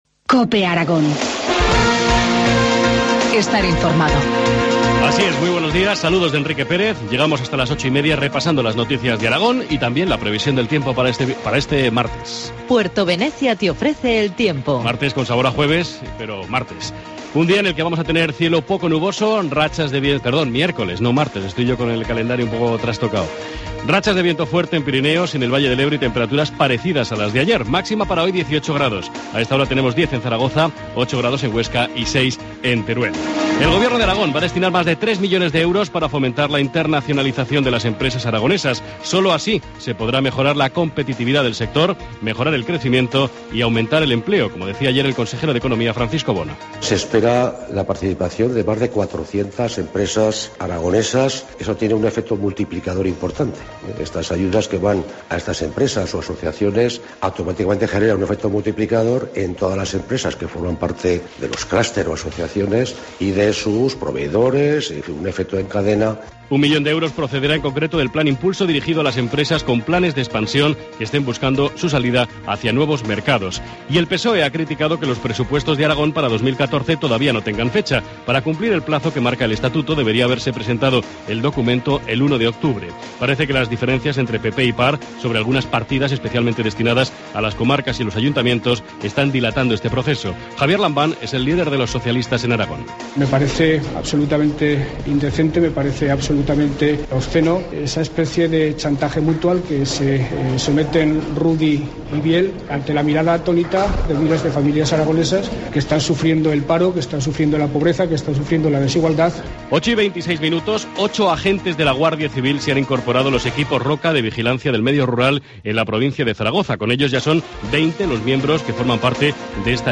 Informativo matinal, miércoles 30 de octubre, 8.25 horas